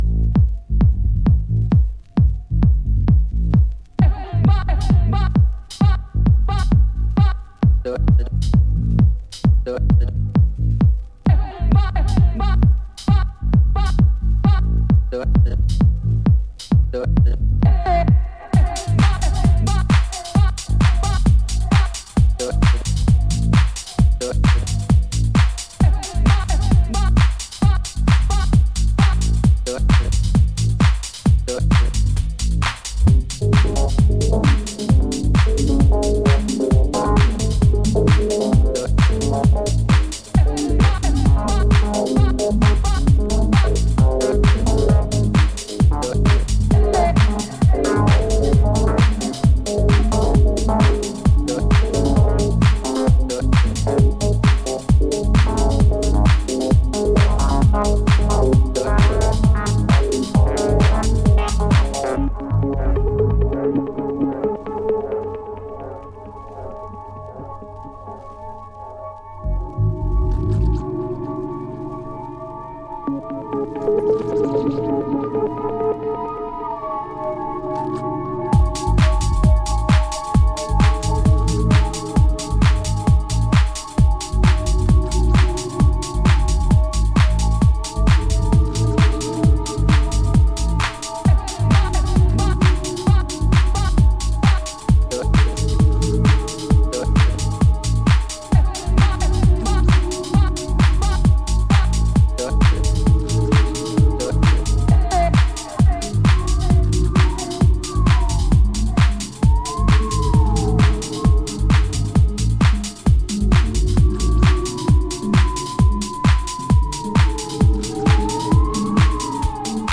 燻んだ音像でブーストするベースラインや凶悪な鳴りのシンセリフ等